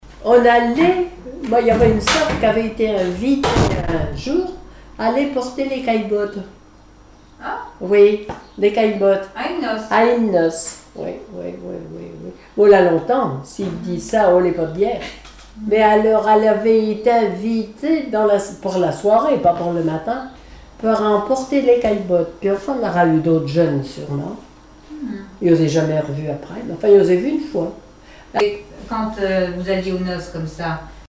Enquête Arexcpo en Vendée-Association Joyeux Vendéens
Catégorie Témoignage